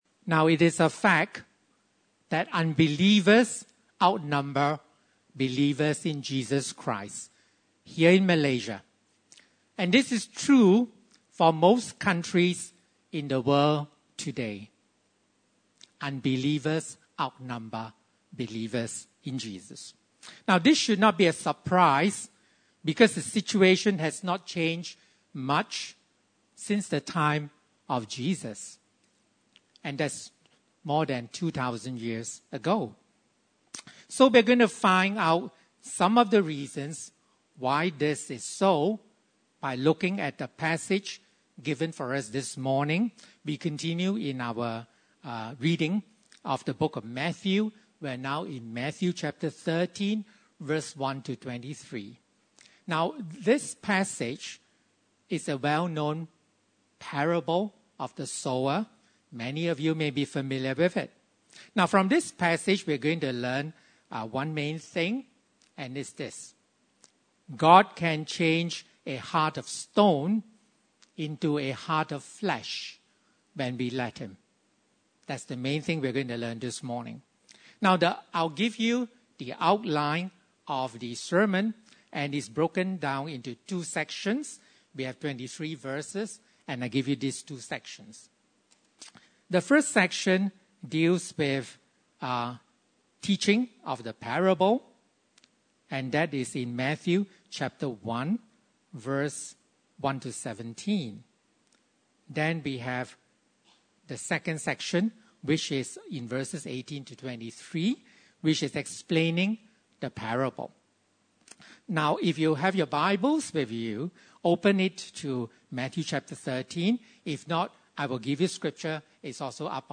Matther 13:1-23 Service Type: Sunday Service (Desa ParkCity) « Committed to Jesus Everlasting Hope in Christ